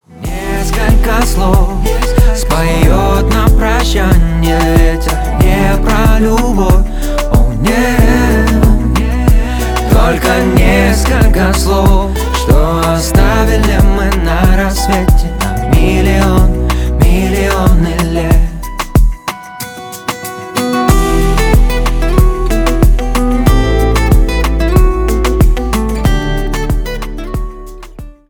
Поп Музыка
спокойные